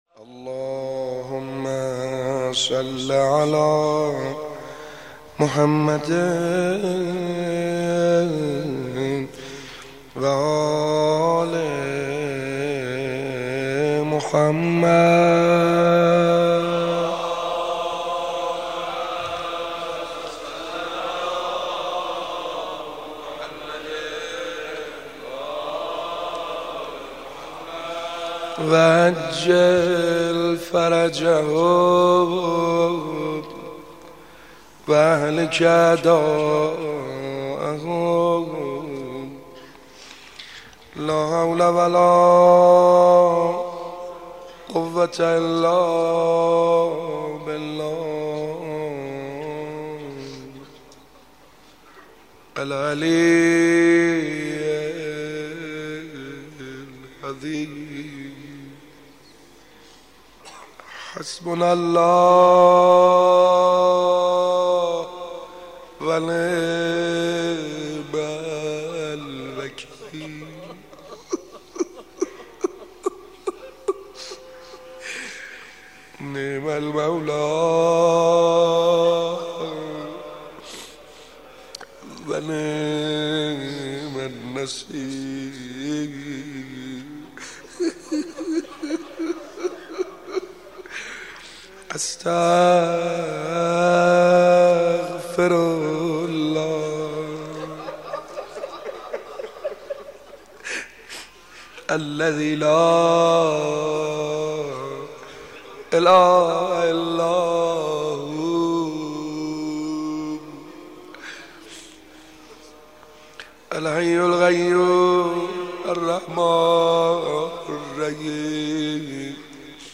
سینه زنی جهاد